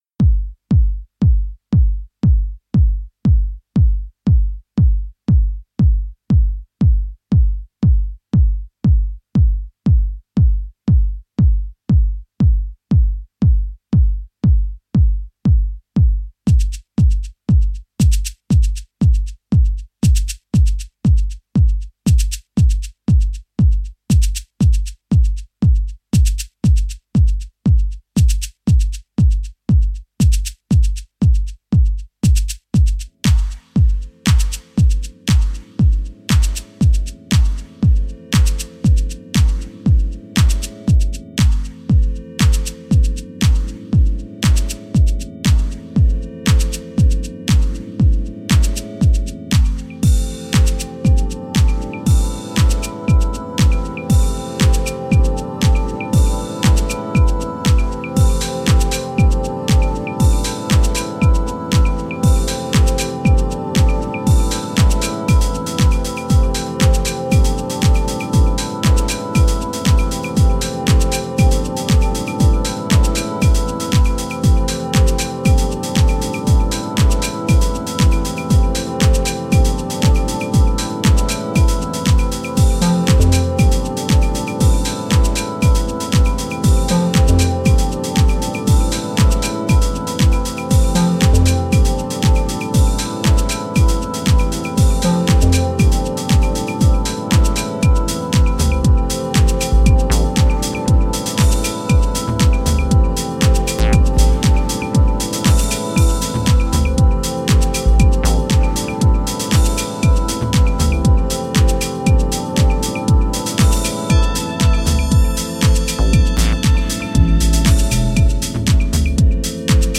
これが中々に素晴らしい内容で、アナログな質感のグルーヴや浮遊コードを駆使したディープ・ハウス群を展開しています。